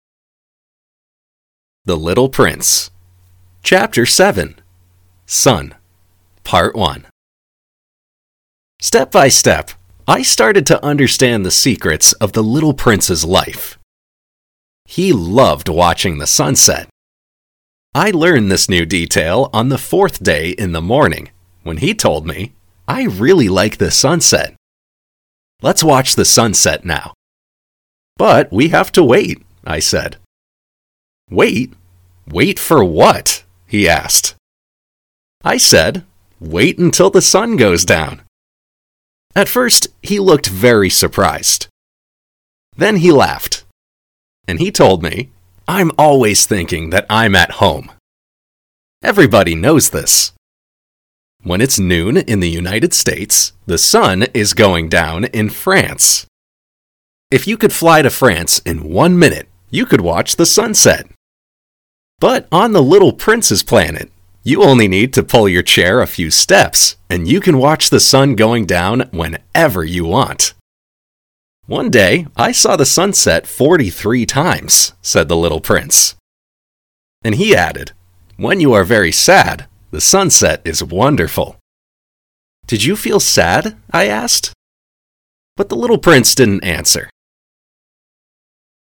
Shadowing
native speakers